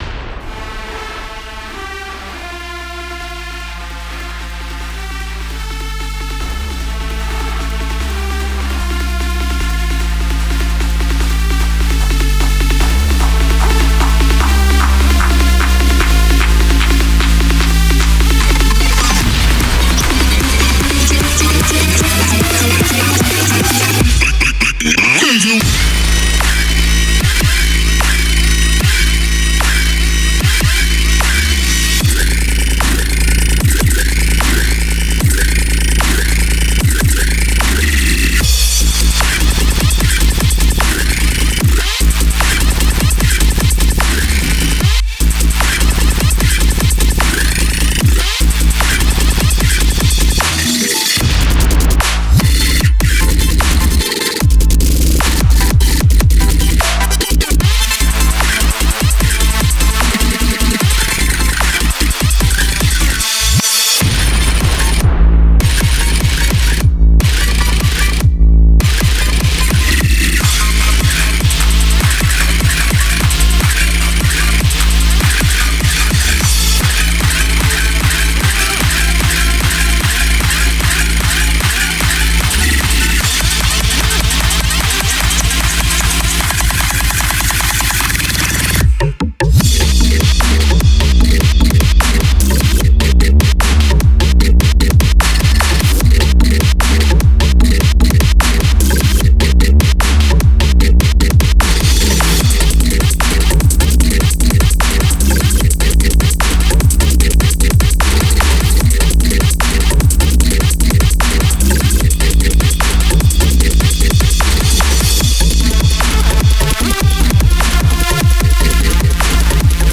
BPM150
MP3 QualityMusic Cut
Honestly a pretty banger dubstep track.
Also includes stops, so the display BPM is sort of a lie.